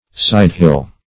Search Result for " sidehill" : The Collaborative International Dictionary of English v.0.48: Sidehill \Side"hill`\, n. The side or slope of a hill; sloping ground; a descent.